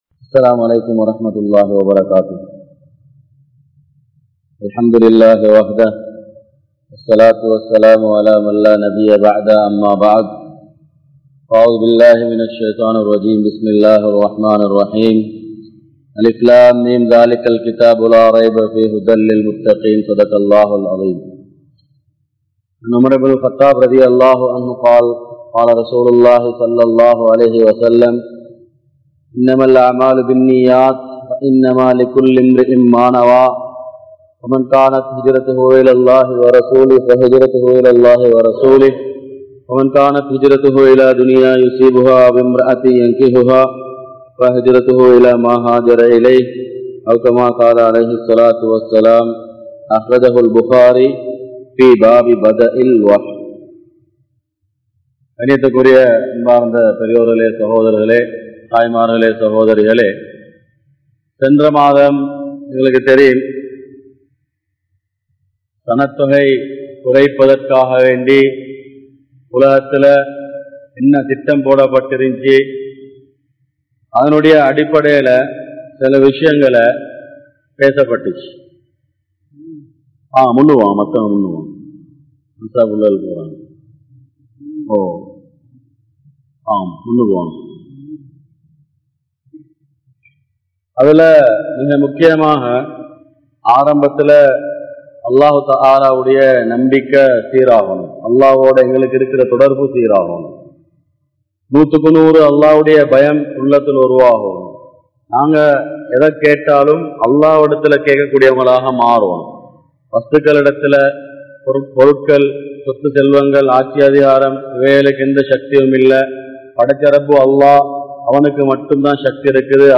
Kudumpa Kattup Paadu - Part 02 | Audio Bayans | All Ceylon Muslim Youth Community | Addalaichenai